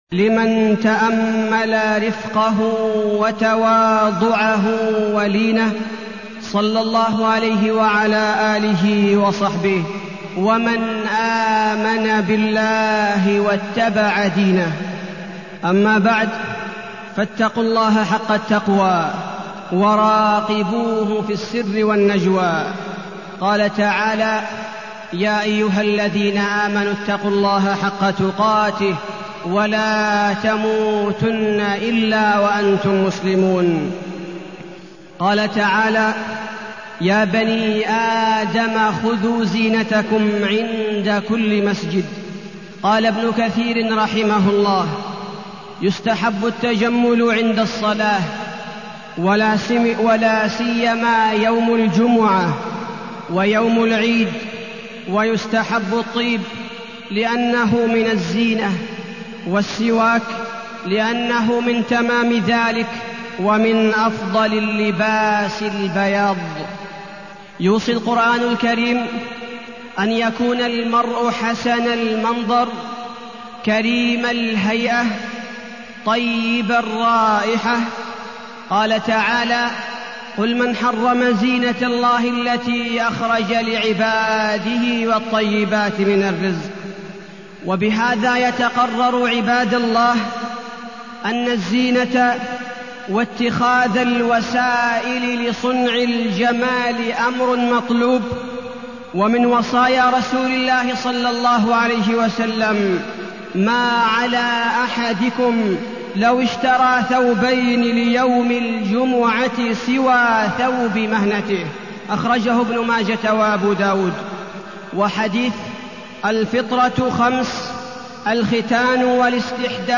تاريخ النشر ١١ ربيع الأول ١٤٢٠ هـ المكان: المسجد النبوي الشيخ: فضيلة الشيخ عبدالباري الثبيتي فضيلة الشيخ عبدالباري الثبيتي اللباس